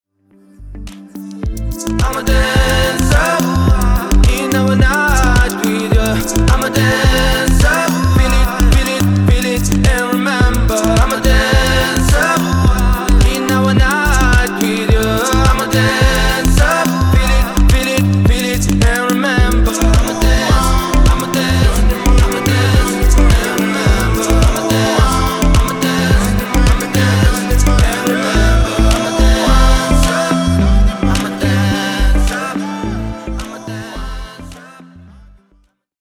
• Качество: 320, Stereo
позитивные
Хип-хоп
заводные